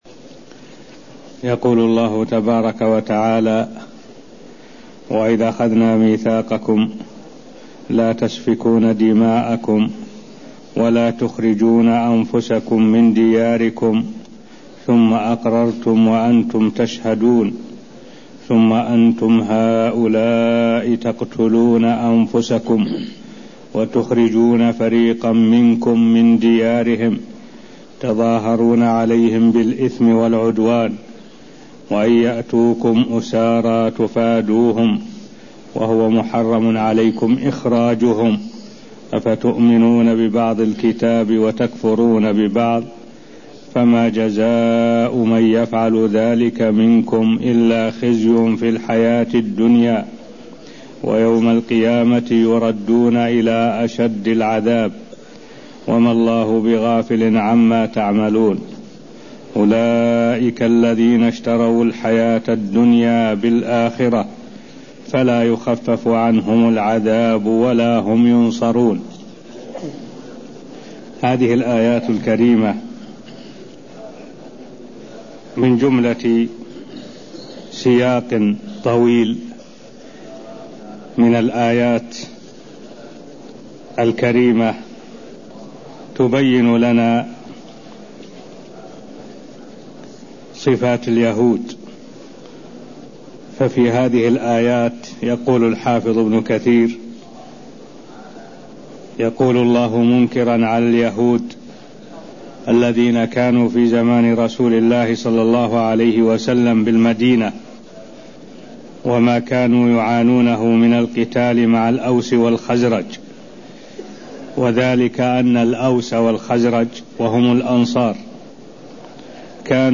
المكان: المسجد النبوي الشيخ: معالي الشيخ الدكتور صالح بن عبد الله العبود معالي الشيخ الدكتور صالح بن عبد الله العبود تفسير آيات من سورة البقرة (0050)